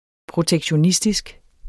protektionistisk adjektiv Bøjning -, -e Udtale [ pʁotεgɕoˈnisdisg ] Oprindelse af protektion og -istisk Betydninger vedr. eller præget af protektionisme Vi må aldrig glemme 1930'erne, som var et protektionistisk årti.